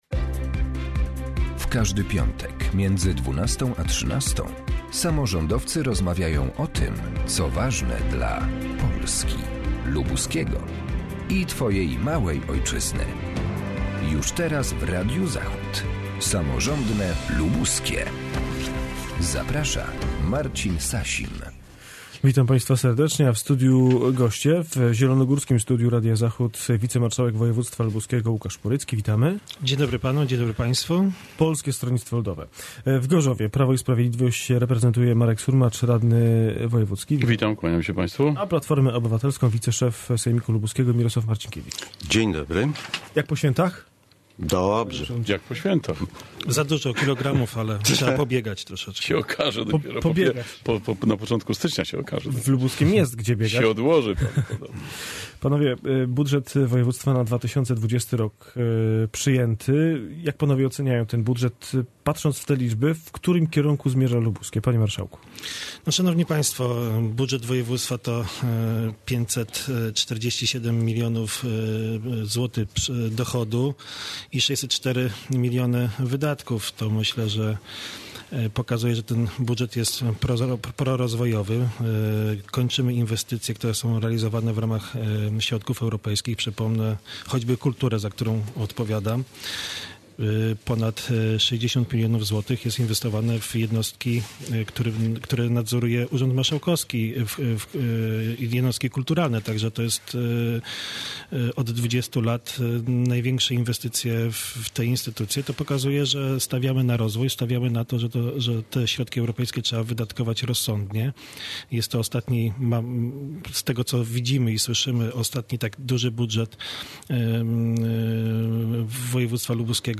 Gośćmi audycji są: Łukasz Porycki – wicemarszałek województwa lubuskiego (PSL), Marek Surmacz – radny wojewódzki (PIS) i Mirosław Marcinkiewicz – wiceprzewodniczący sejmiku (PO).